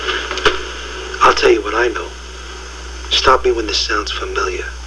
Stop _ (Chazz From Usual Suspects, Stop me when this sounds... speech) 104KB